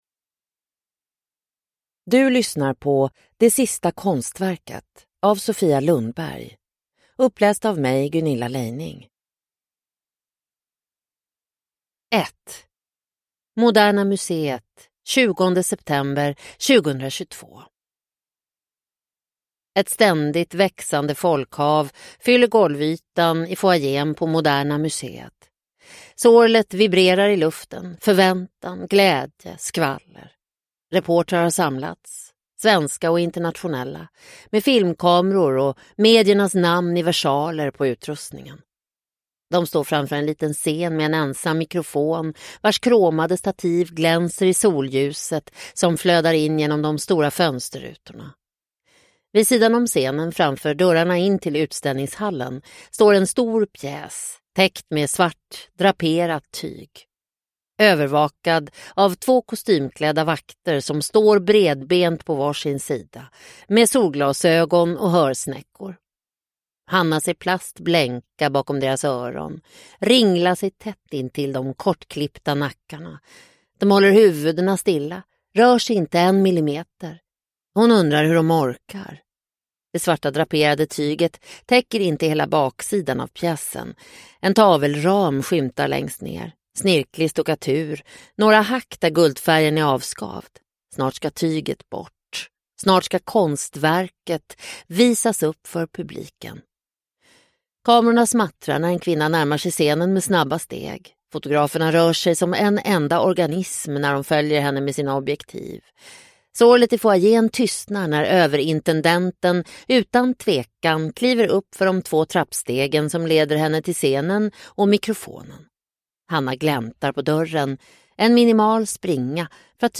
Det sista konstverket – Ljudbok – Laddas ner